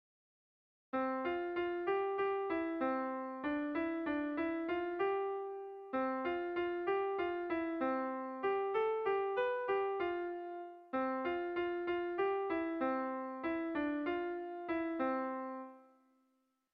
Erlijiozkoa
A1A2A2